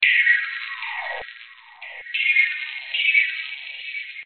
深渊垫
标签： 慢节奏 循环 环境
声道立体声